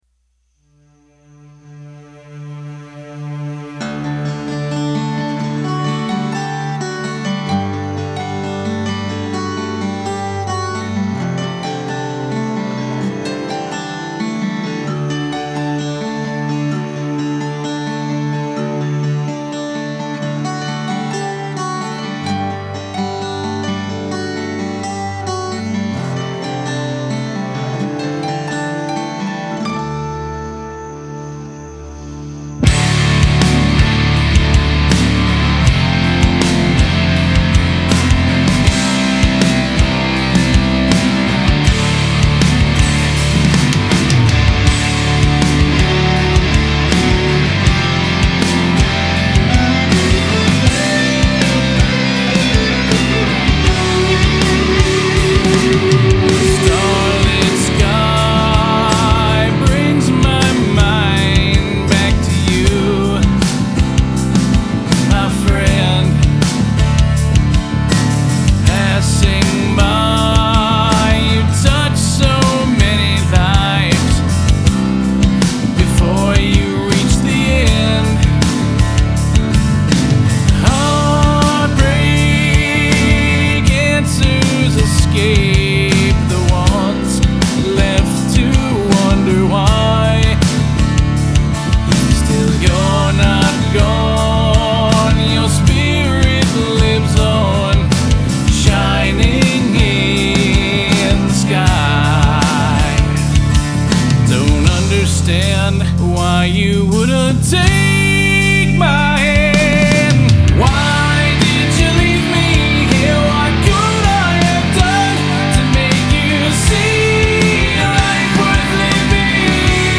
He was playing the acoustic riff over and over and just talking about his friend and the lyrics just materialized.
The solo guitars are "scratch" and have to be redone. The bridge and outro are also missing some solo work I intend to record.